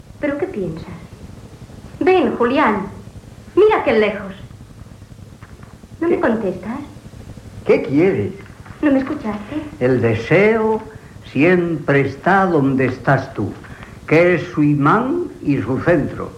Fragment de l'adaptació radiofònica de l'obra "El gran galeoto" de José Echegaray.
Ficció